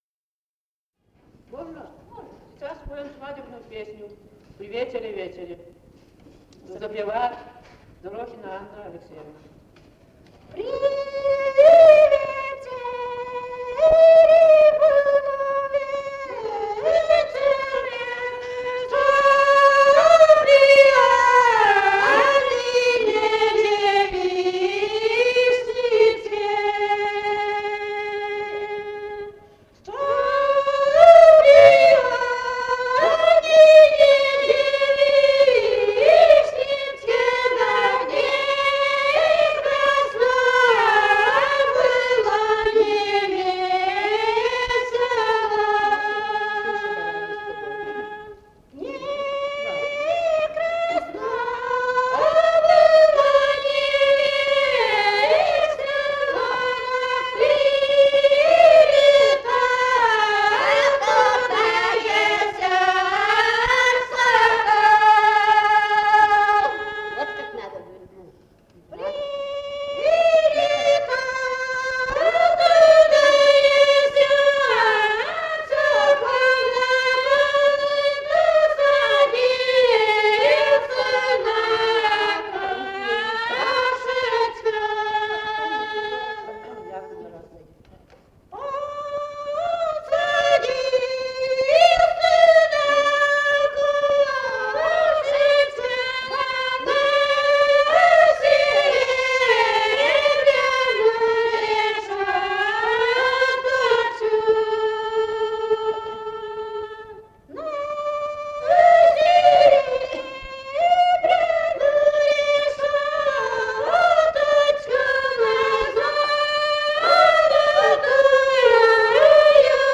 «При вечере, было вечере» (свадебная).